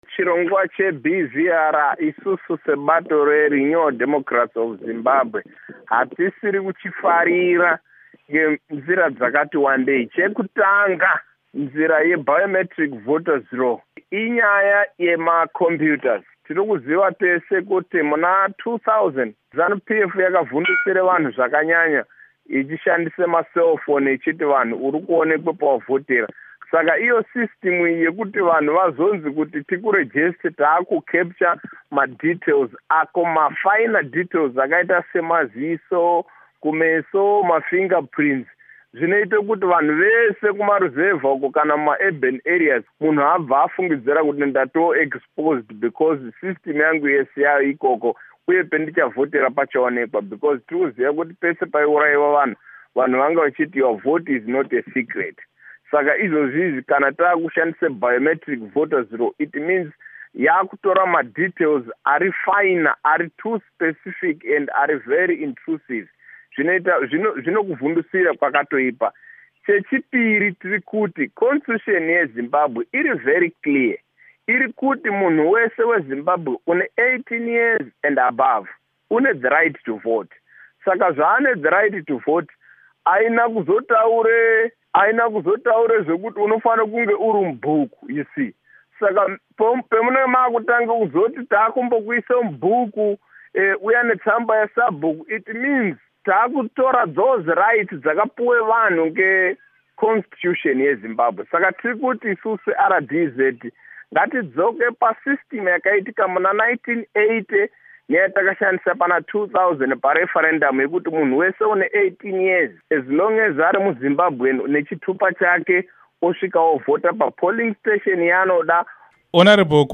Hurukuro naMuzvare Thabitha Khumalo naVaPishai Muchauraya